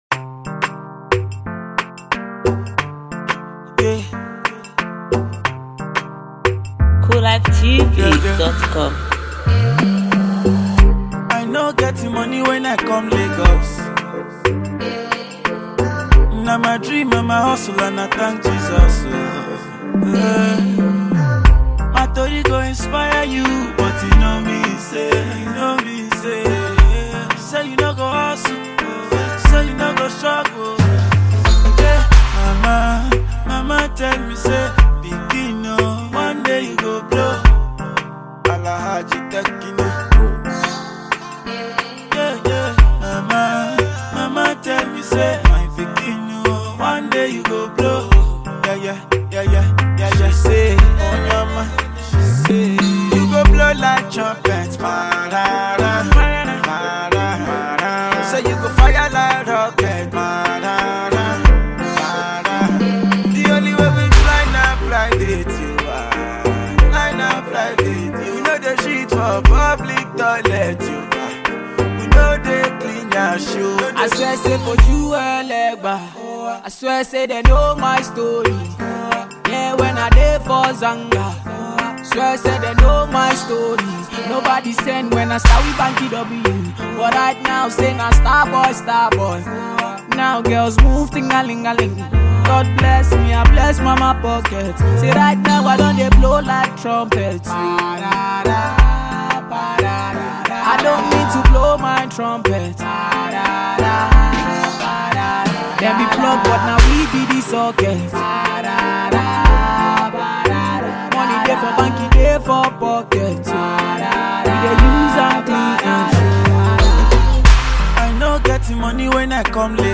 powerful vocal